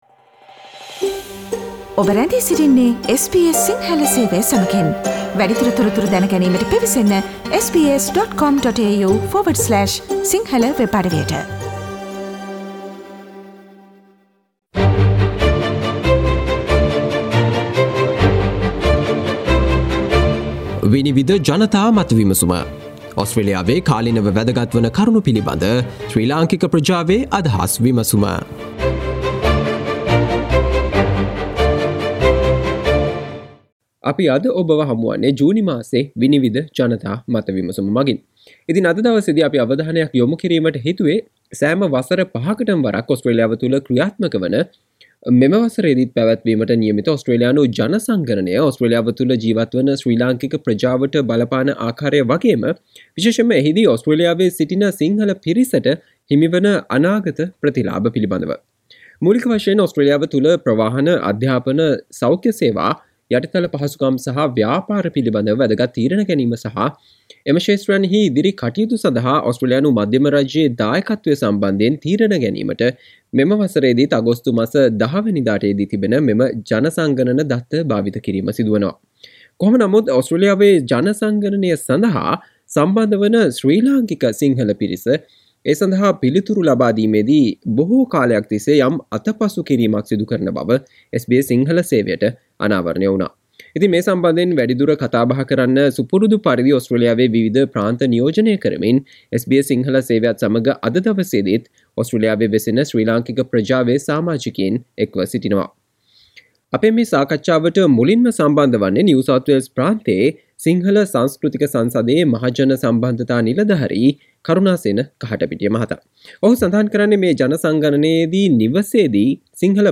ඔස්ට්‍රේලියානු ජන සංගණනයේදී, ඉංග්‍රීසි භාෂාවට අමතරව නිවසේදී "සිංහල" භාෂාව භාවිත කරන බවට සඳහන් කිරීම මගින් ඕස්ට්‍රේලියාවේ සිටින සිංහල පිරිසට හිමි වන අනාගත ප්‍රතිලාබ පිළිබඳව ඕස්ට්‍රේලියාවේ ශ්‍රී ලංකික ප්‍රජාවේ අදහස් වලට සවන් දෙන්න මෙම මැයි මාසයේ SBS සිංහල ගුවන් විදුලියේ 'විනිවිද' ජනතා මත විමසුම තුලින්